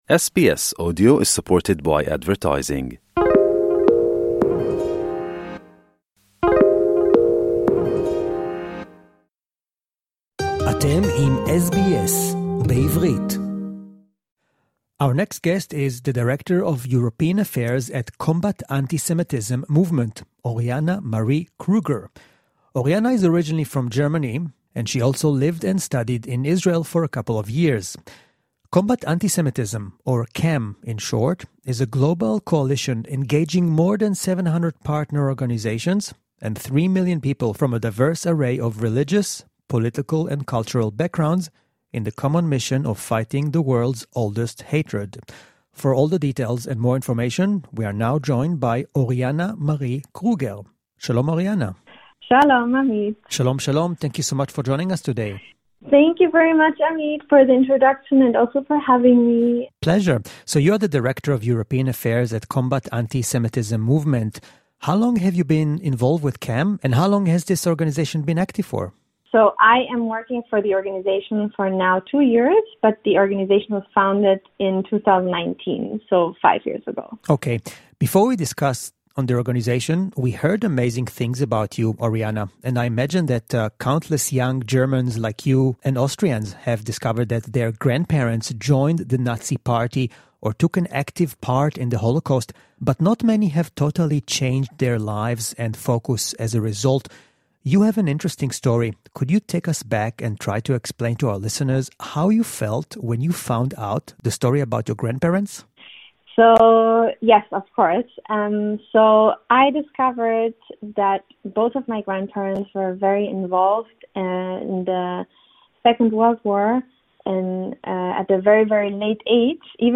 briefly touched upon in the interview